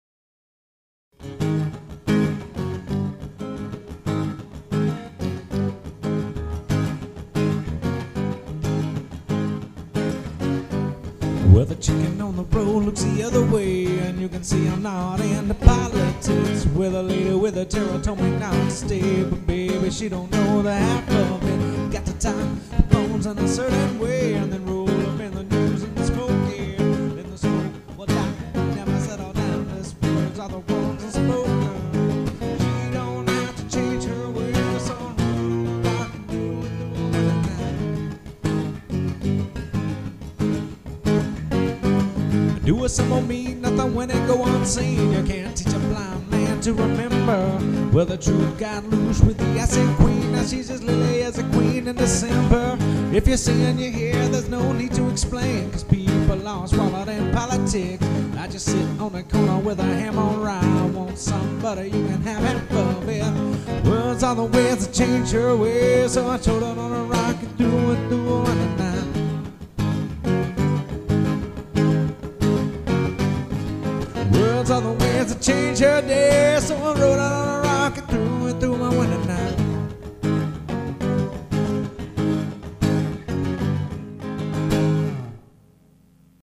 Acoustic Guitar
Vocals